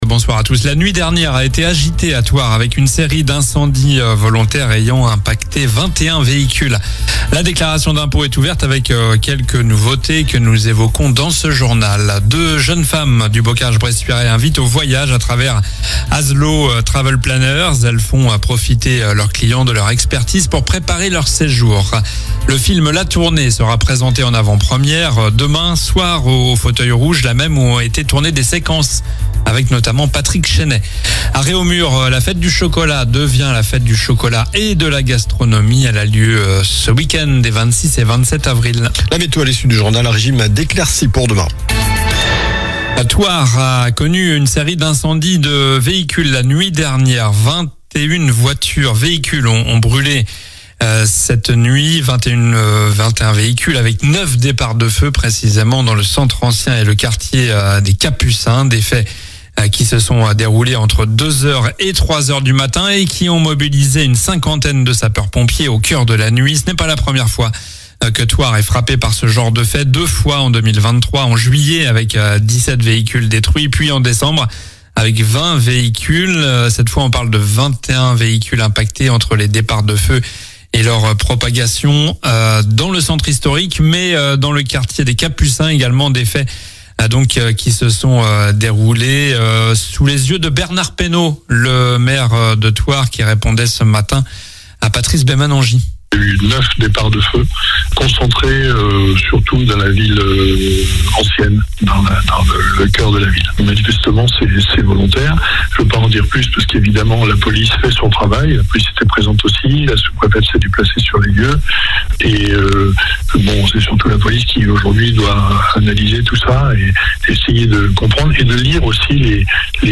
Journal du mercredi 23 avril (soir)